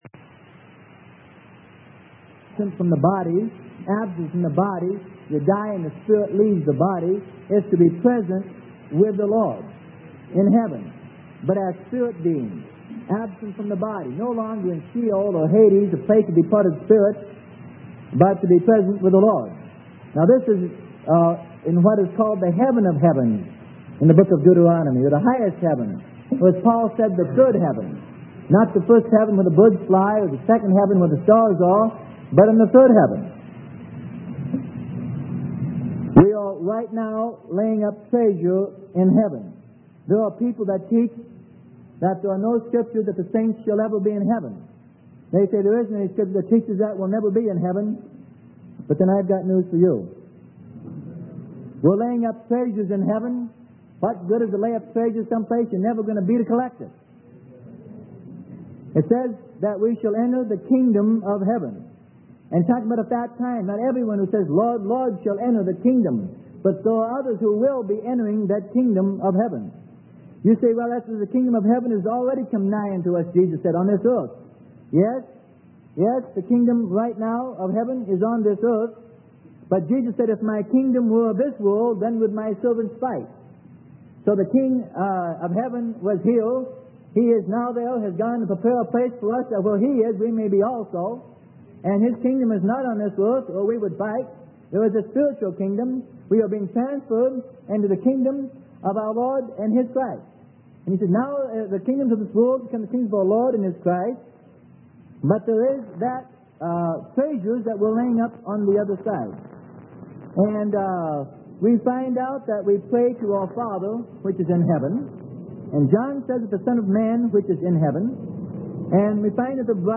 Sermon: Over There - Heaven - Freely Given Online Library